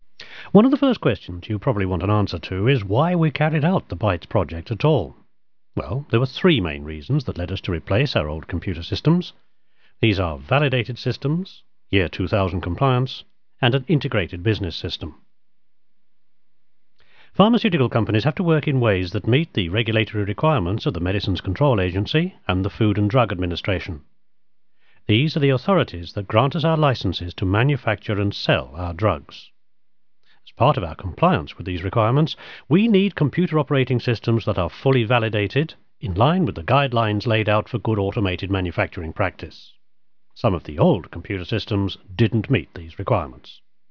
Teeafit Sound & Vision has a sophisicated audio facility, idea for the recording of spoken-word material.
This is another technical voiceover, for a pharmeceutical company that needed to introduce a new computer system to its staff.